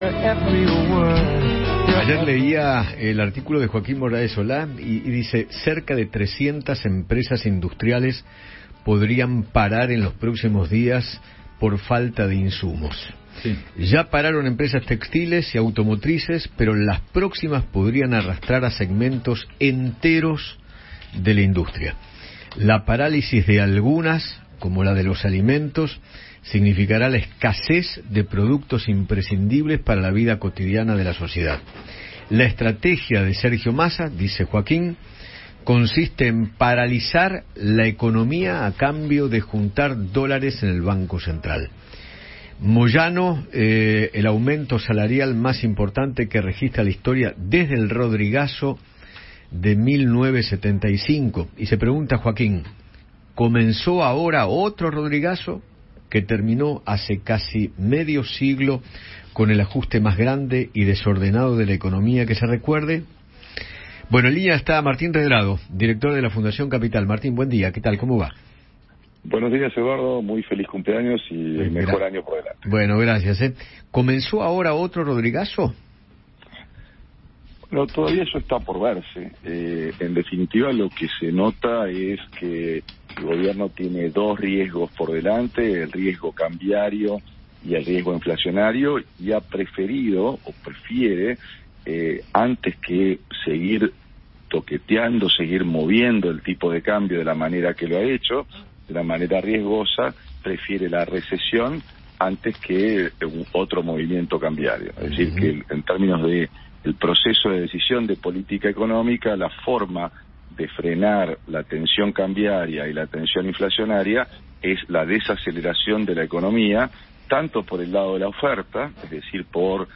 Martín Redrado, economista y director de la Fundación Capital, habló con Eduardo Feinmann sobre las nuevas medidas económicas anunciadas por el ministro Sergio Massa y su efecto en el sector empresarial.